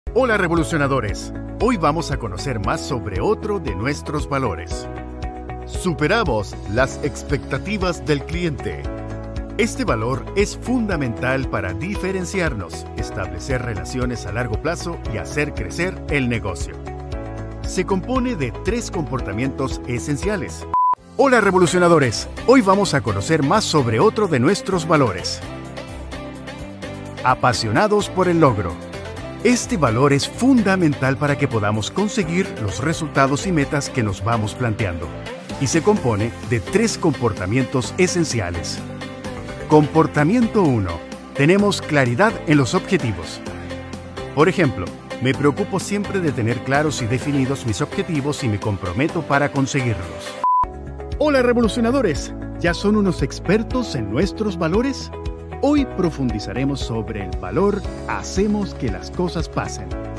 Spaans (Latijns Amerikaans)
Commercieel, Natuurlijk, Speels, Veelzijdig, Zakelijk
Explainer